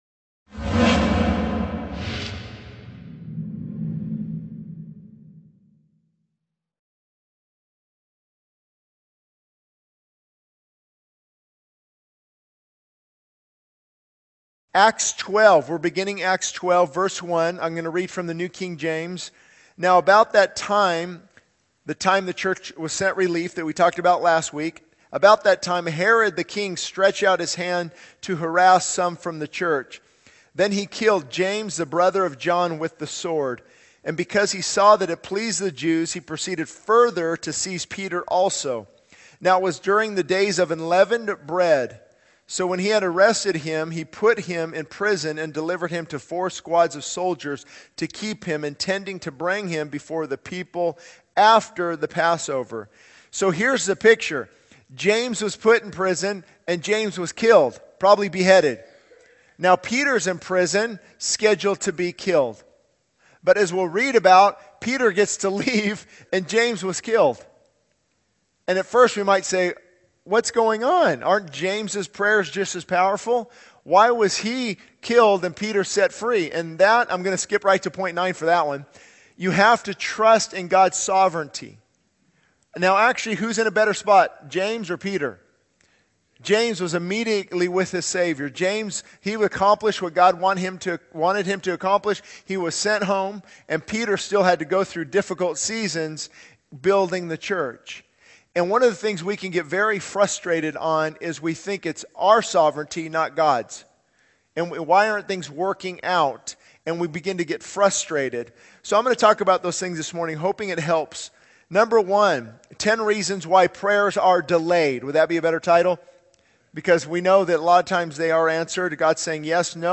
This sermon on Acts 12 emphasizes the importance of trusting in God's sovereignty, especially in times of delay and unanswered prayers. It highlights the need for self-examination, repentance, and a right attitude in prayer. The sermon also points to the true God who answers prayers and the significance of praying in Jesus' name.